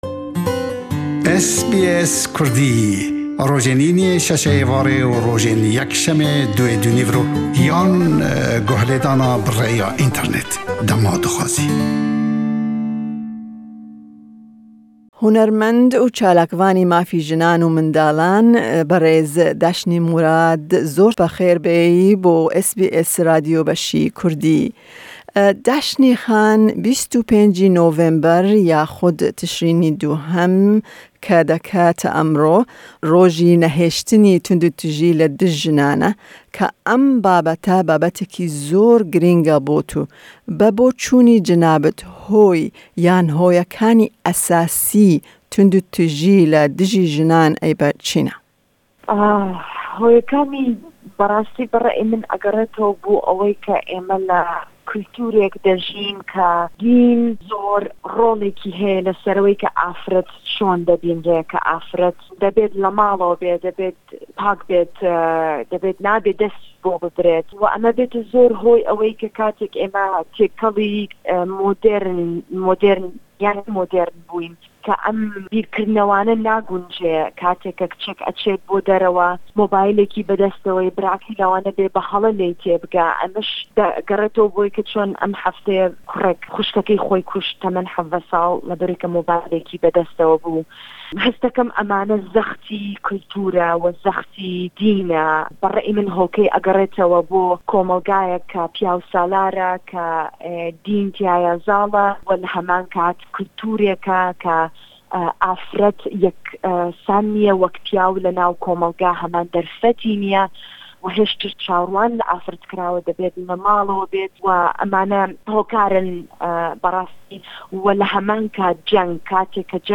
Em derbarê Roja Navnetewî ya Nehîştina Tundûtûjiya li jinan dibe bi hunermend û çalavana mafê jinan xatun Deşnê Morad (Dashni Morad) re axifîn.